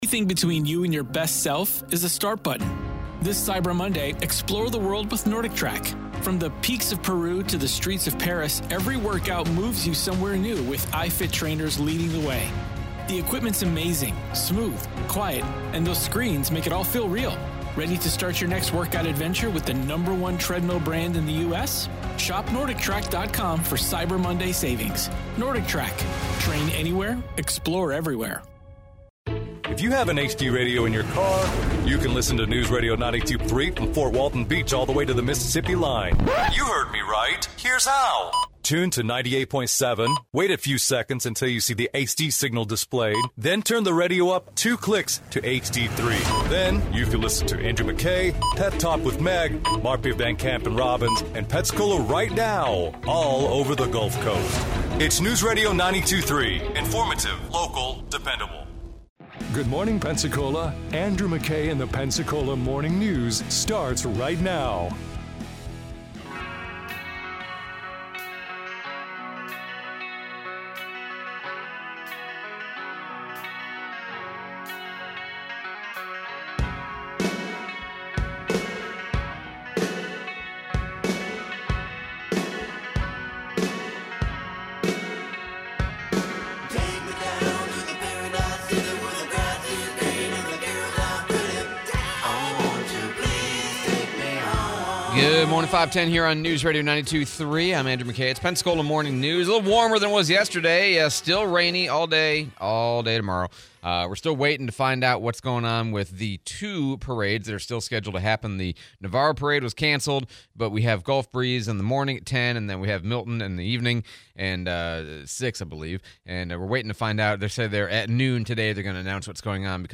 Teens charged with murder in Santa Rosa, interview